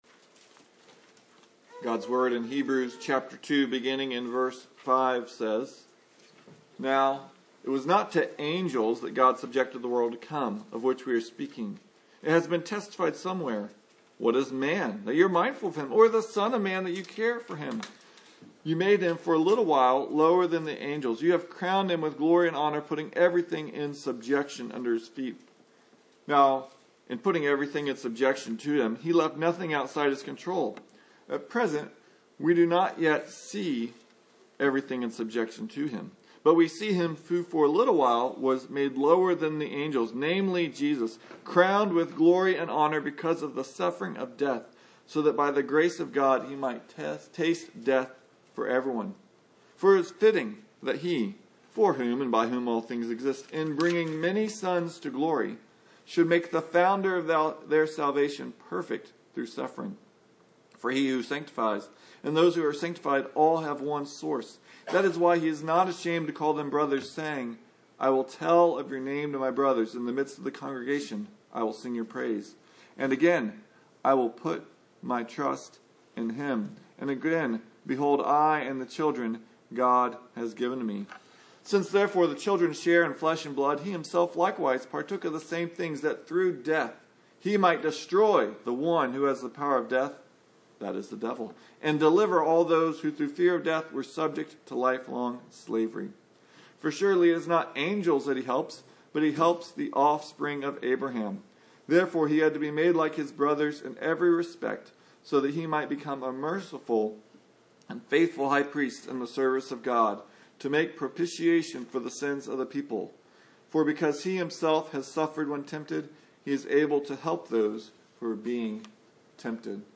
Hebrews 2:10-18 Service Type: Sunday Morning Jesus’ incarnation is celebrated worldwide